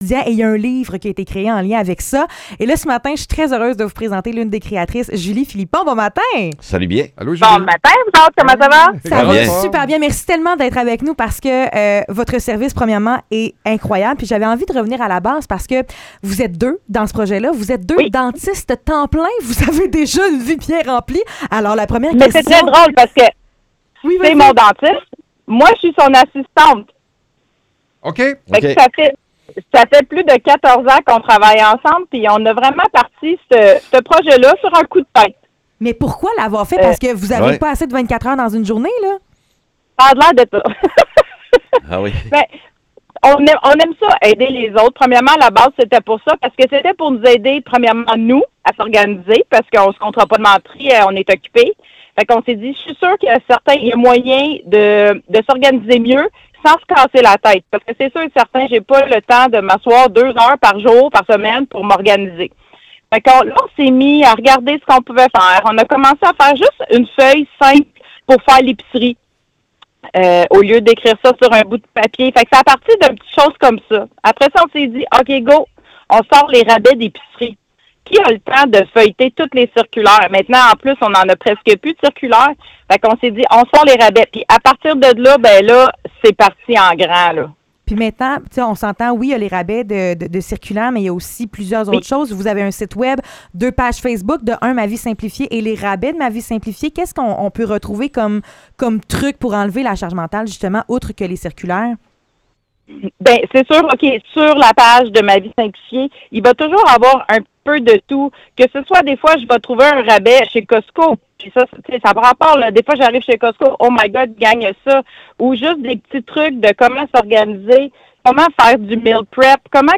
Entrevue MA VIE SIMPLIFIÉE